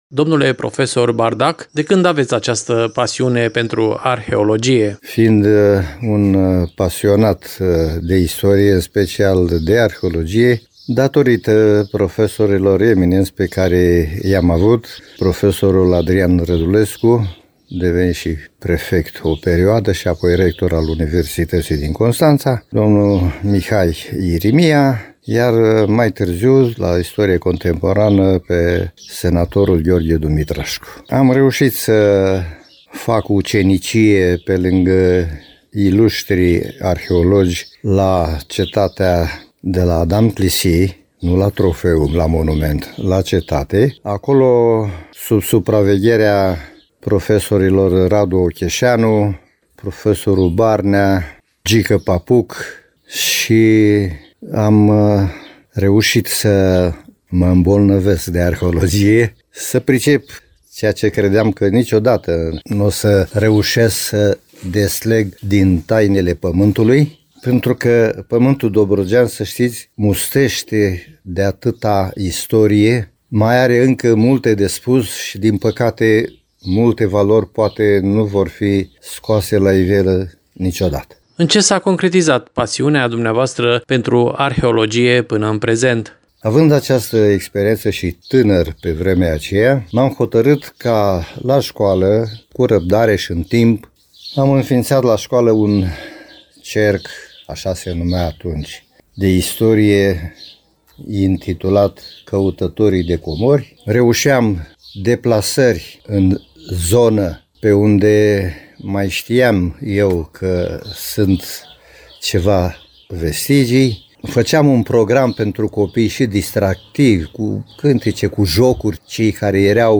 AUDIO | Comorile Dobrogei. Interviu